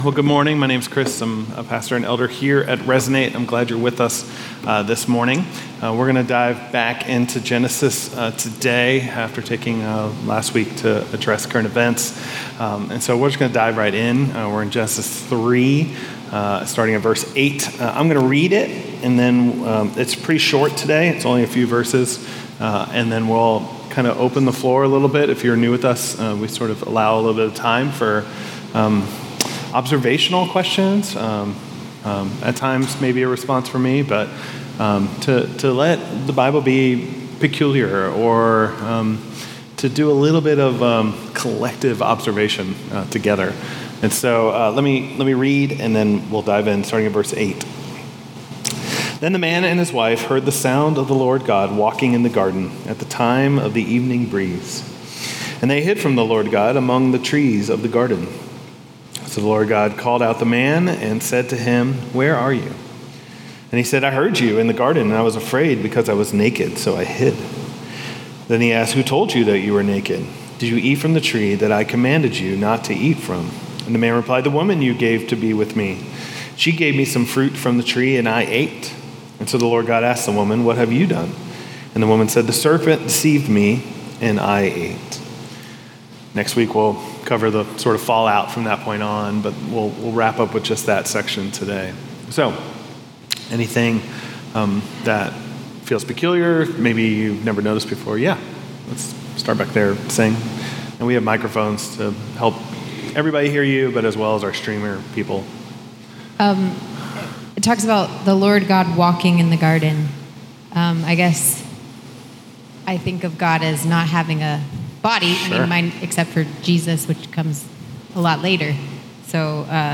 This sermon explores how shame distorts our identity, drives us into hiding, and fractures our relationships, but also how God’s first movement toward fallen humanity is grace. From Eden to the cross, God refuses to leave us in our hiding.